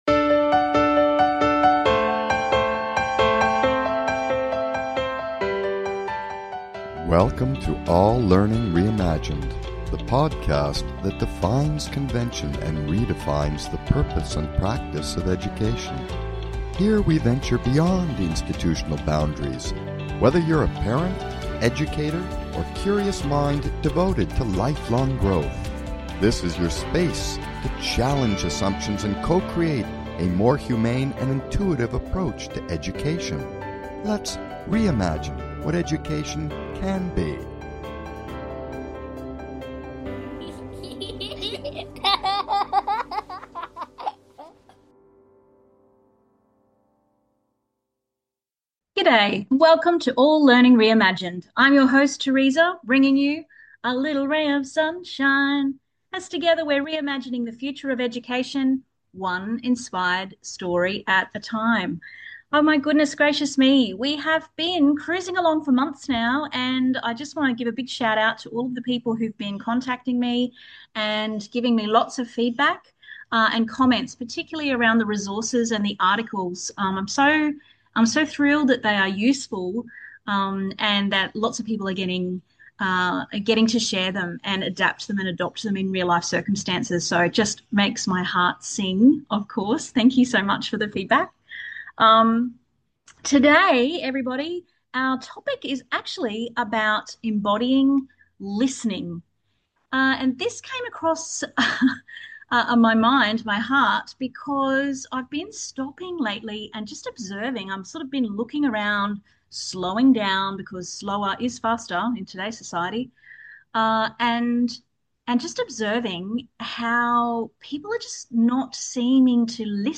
Talk Show Episode, Audio Podcast, All Learning Reimagined and Embodying Listening on , show guests , about Embodying Listening, categorized as Comedy,Education,Entertainment,Games & Hobbies,Health & Lifestyle,Kids & Family,Philosophy,Self Help,Society and Culture